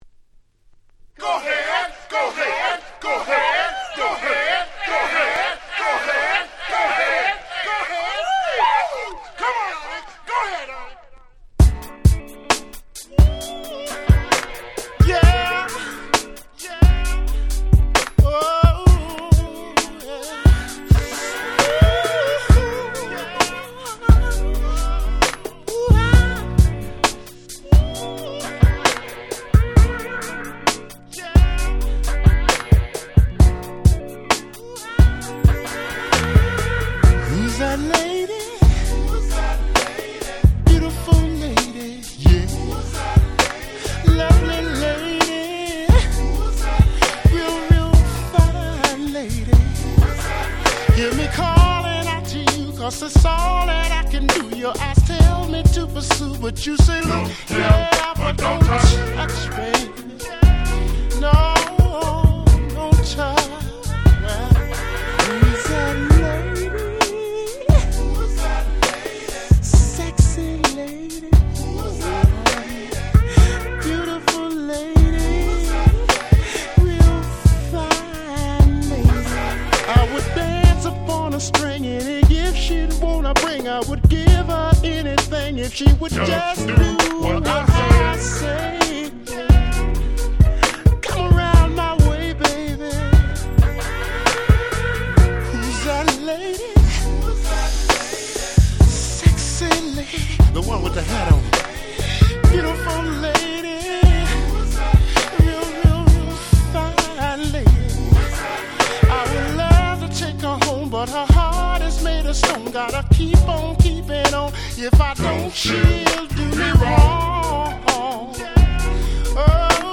04' Very Nice Remixes !!